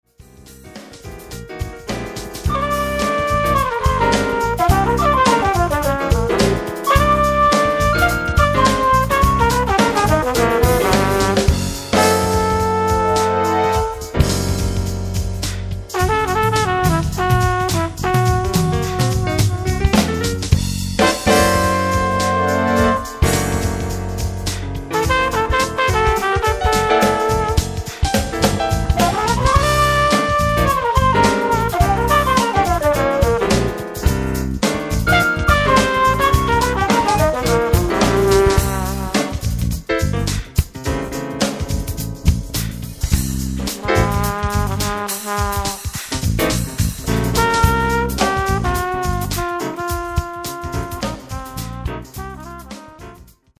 Trumpet
Piano
Drums 1.